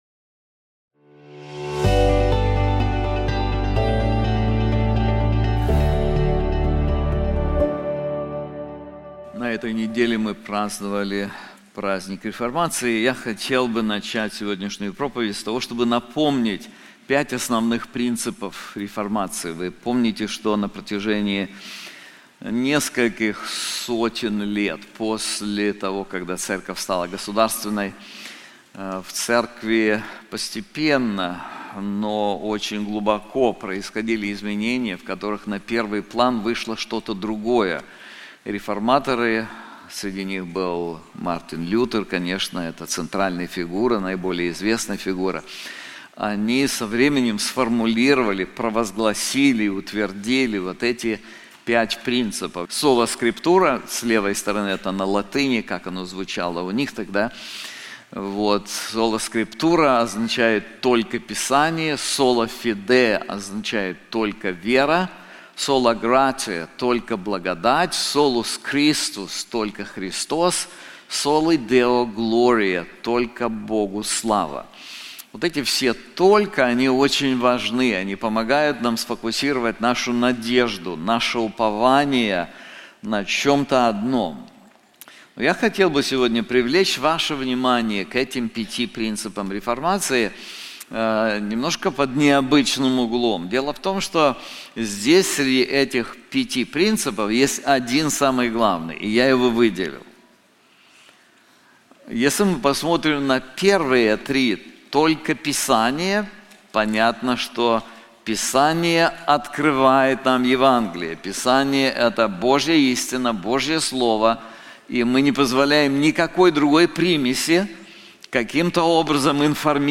This sermon is also available in English:The Servant of the LORD • Isaiah 49:1-4